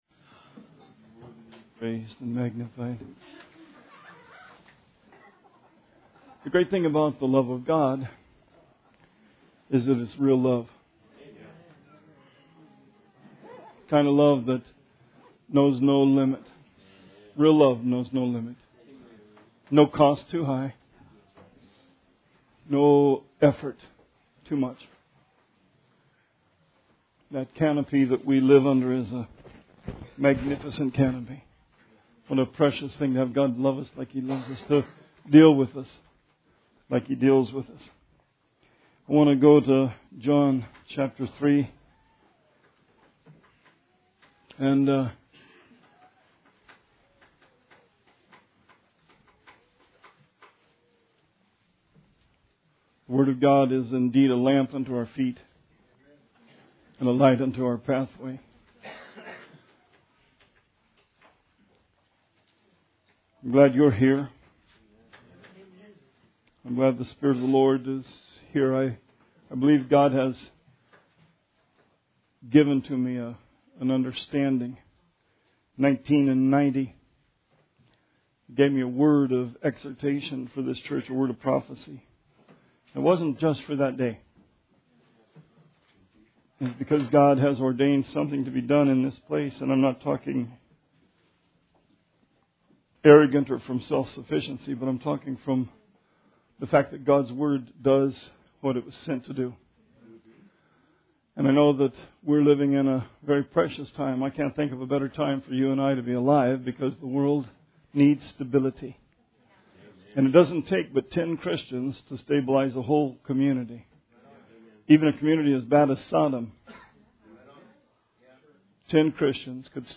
Sermon 1/22/17